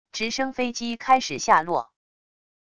直升飞机开始下落wav音频